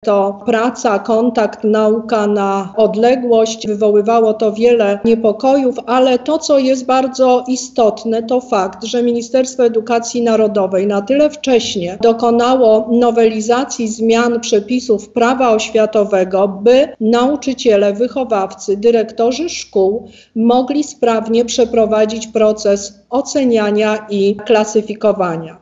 – Dobiega końca ich edukacja szkolna, a jej ostatni miesiąc przebiegał w zupełnie odmiennej formie – mówi Lubelska Kurator Oświaty, Teresa Misiuk (na zdjęciu).